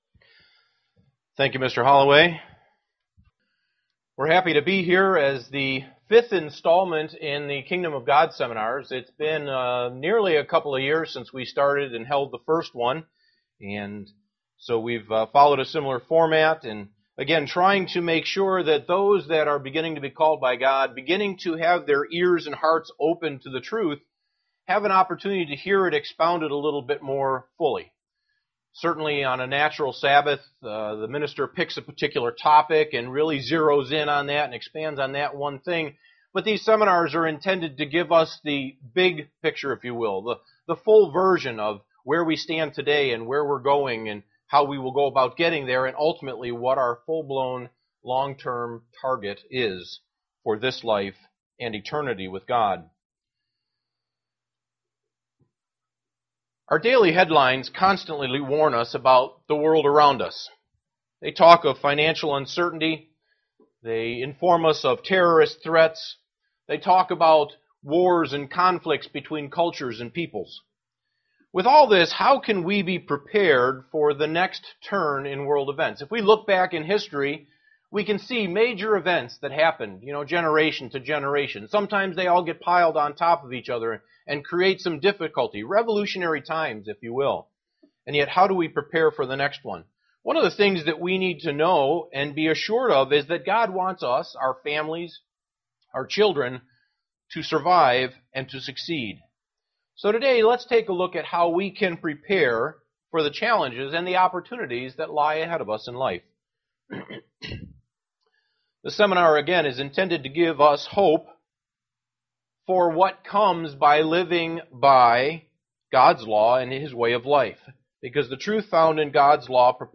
Given in Elmira, NY
Print KOG Seminar 1) Why we don't have world peace. 2) Why the Kingdom of God is the Solution UCG Sermon Studying the bible?